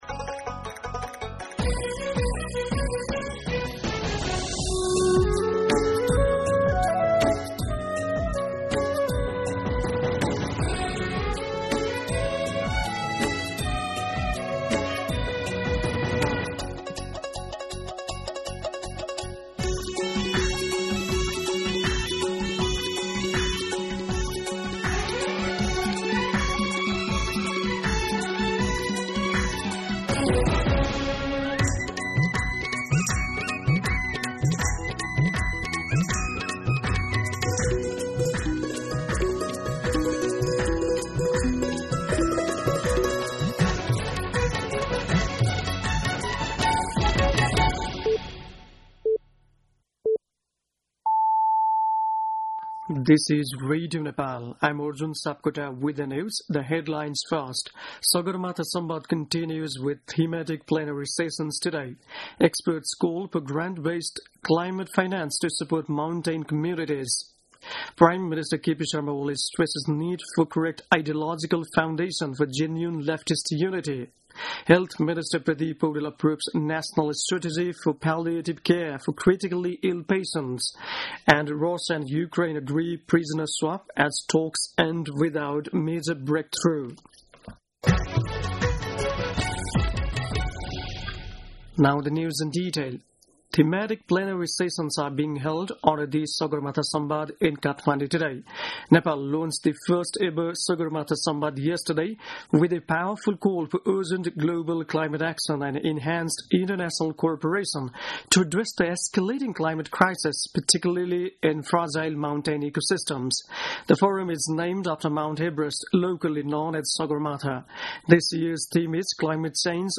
An online outlet of Nepal's national radio broadcaster
दिउँसो २ बजेको अङ्ग्रेजी समाचार : ३ जेठ , २०८२
2-pm-English-News-3.mp3